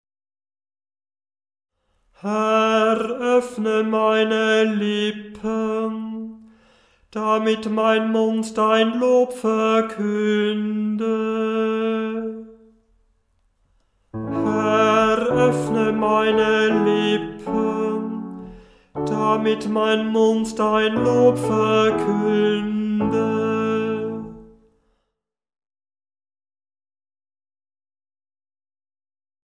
Beim Stundengebet übernimmt nach der Einleitung ein Kantor/eine Kantorin das Anstimmen der Gesänge.
Beim Stundengebet selber wird der Ruf natürlich nur einmal gesungen, die mehrmaligen Wiederholunge mögen das Üben erleichtern.
Herr, öffne meine Lippen, Gl 614,1 720 KB Erster Ton a, wie notiert